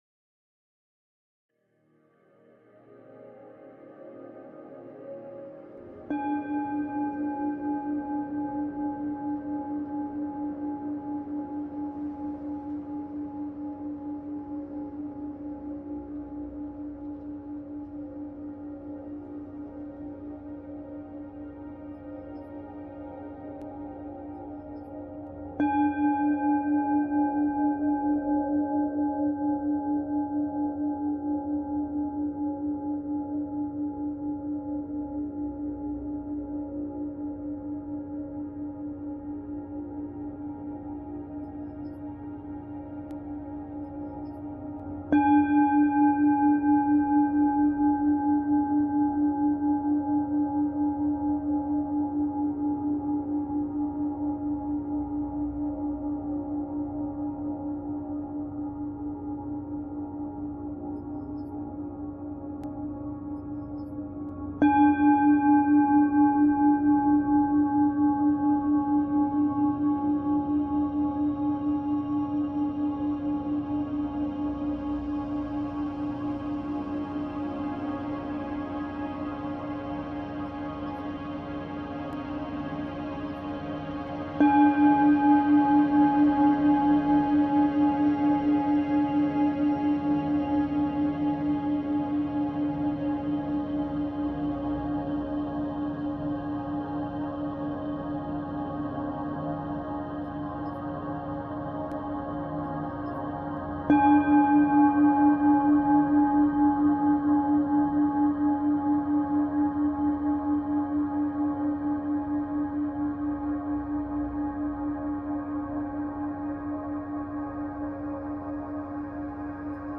La fréquence 285hz au son du bol tibétain, la réparation du dommage corporel, et l’harmonisation des différentes organes du corps
285hz-chants-bolt-tibetain-dla-reparation-du-dommage-corporel-Harmonisation-des-organes-du-corp.mp3